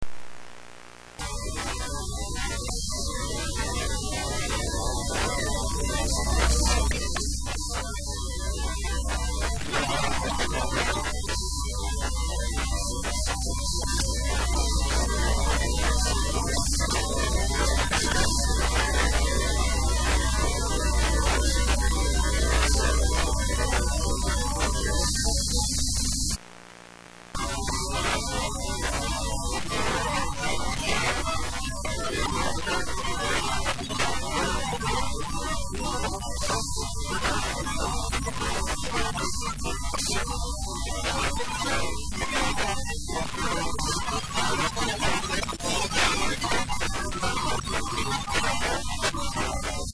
evp of Sun Inn man singing along